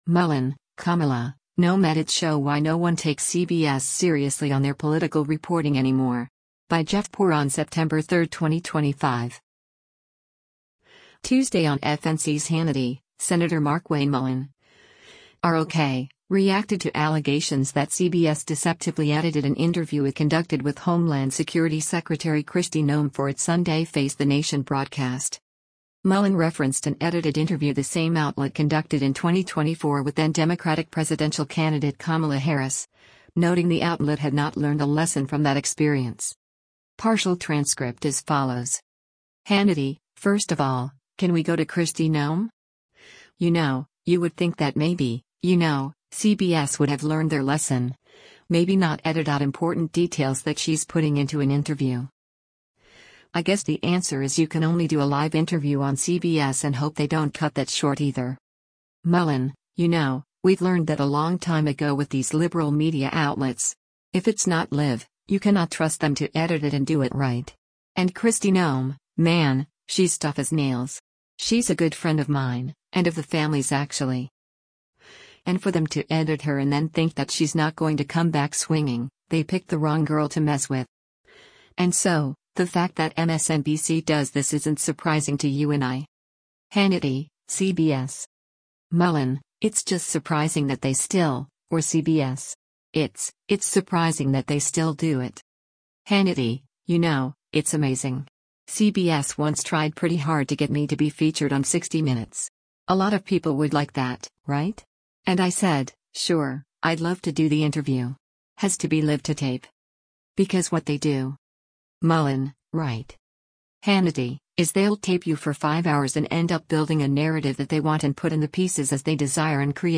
Tuesday on FNC’s “Hannity,” Sen. Markwayne Mullin (R-OK) reacted to allegations that CBS “deceptively” edited an interview it conducted with Homeland Security Secretary Kristi Noem for its Sunday “Face the Nation” broadcast.